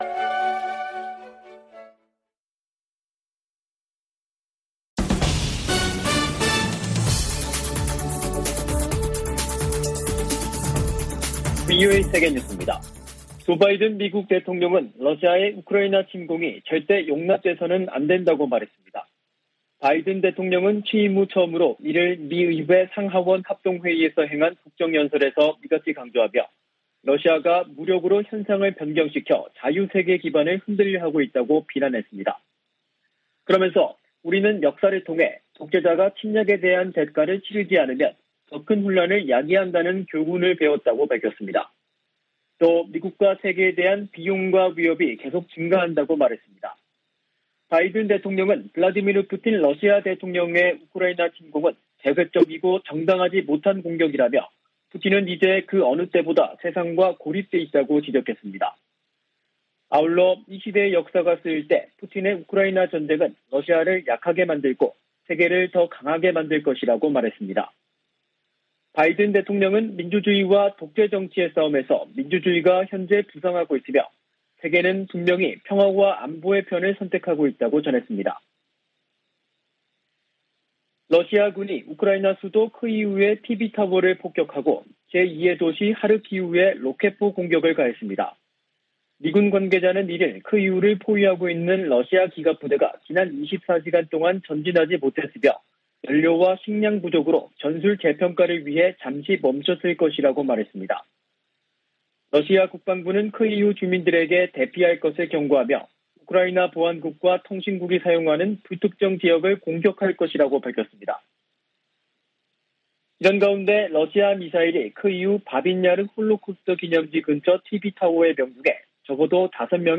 VOA 한국어 간판 뉴스 프로그램 '뉴스 투데이', 2022년 3월 2일 2부 방송입니다. 조 바이든 미국 대통령이 취임 후 첫 국정연설에서 러시아의 우크라이나 침공을 강력 비판했습니다.